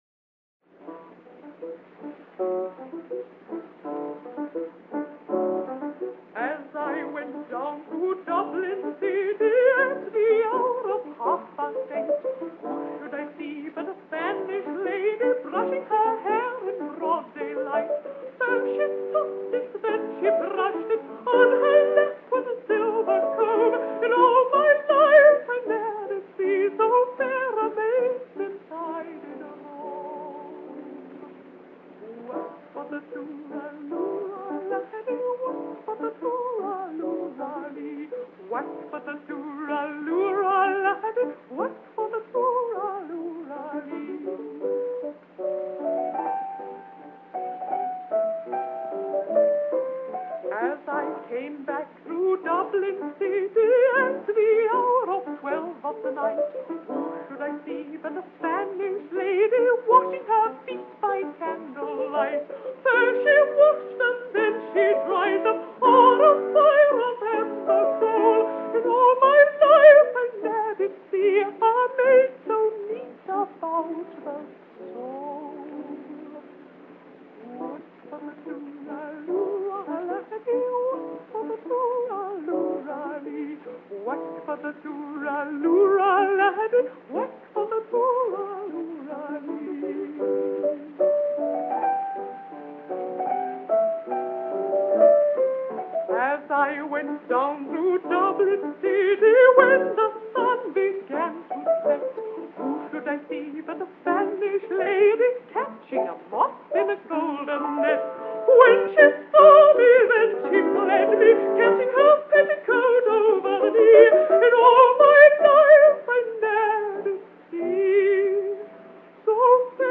用情極為深切，聲聲有情，字字有淚。